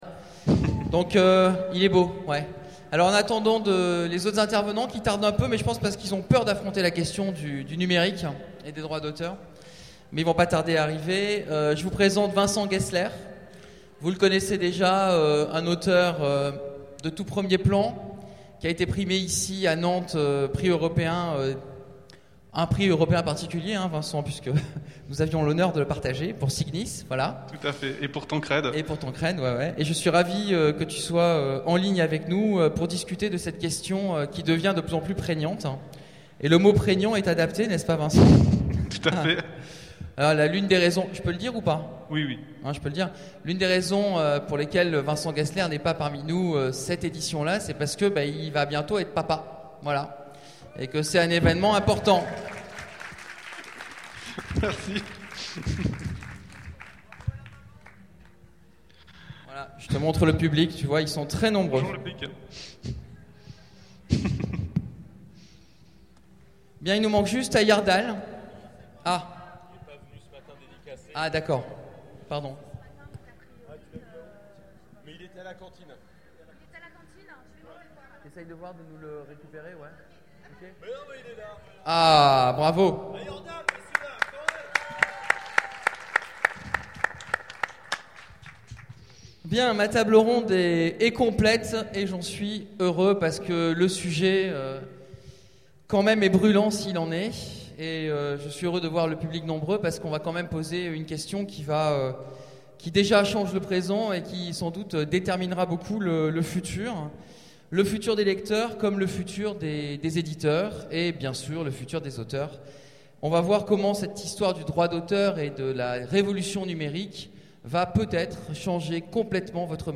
Utopiales 12 : Conférence Droits d’auteur et révolution numérique
Conférence